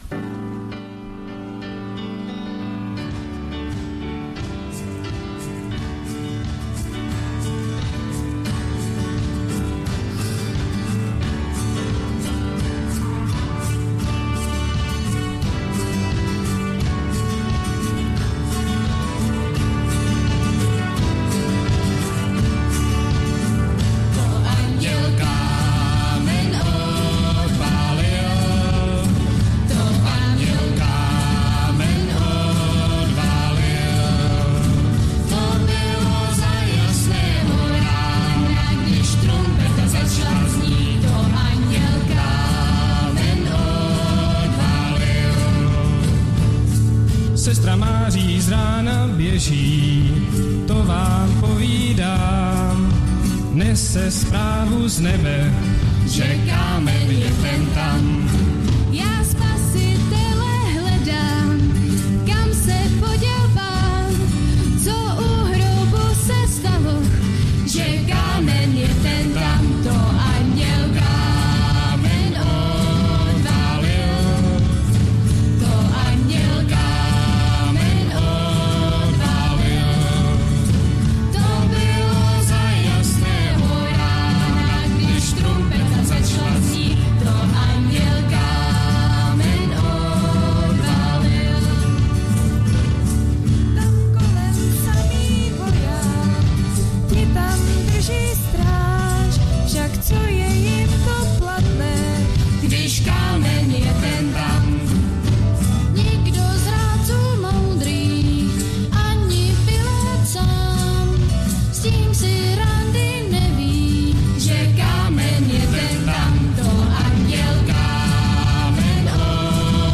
Kategorie: Velkopáteční bohoslužby